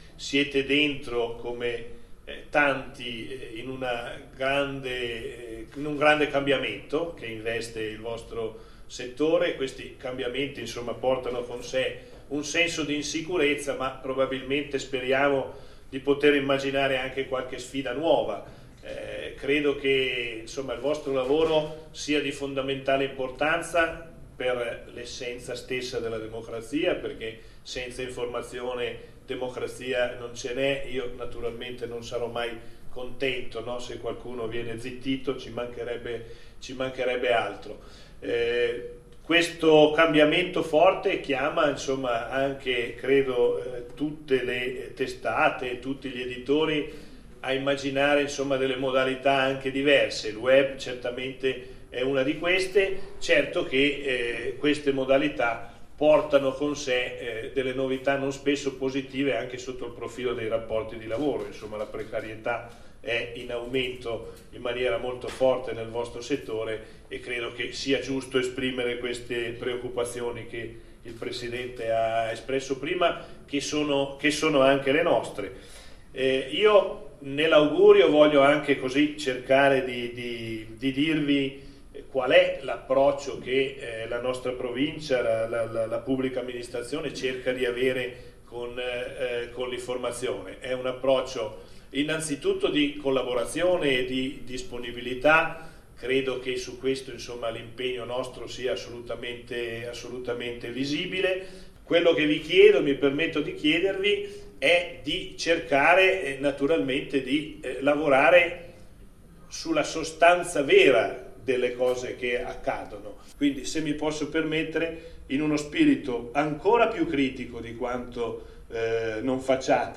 Stamani il tradizionale scambio di auguri presso il palazzo della Provincia
presidente_rossi-saluto_ai_giornalisti.mp3